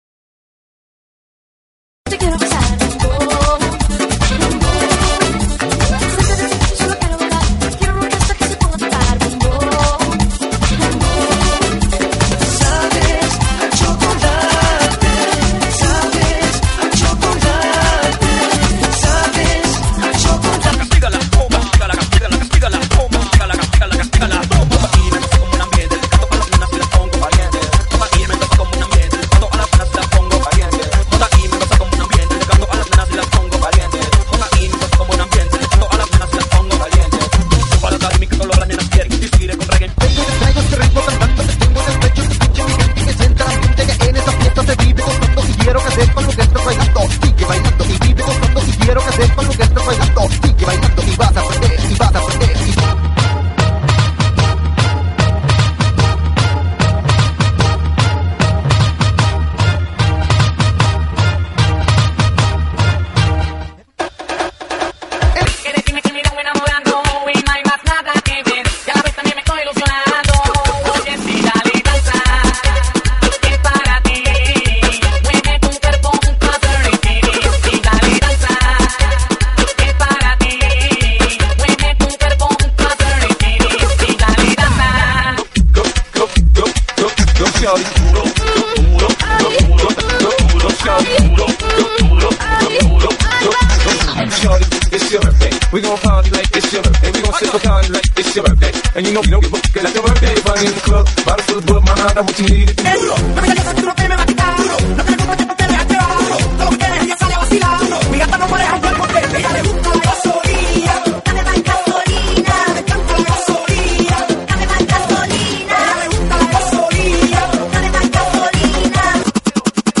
GENERO: REGGAETON